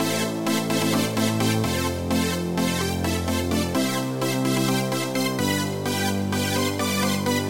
恍惚的旋律
描述：128bpm
Tag: 128 bpm Trance Loops Synth Loops 1.26 MB wav Key : Unknown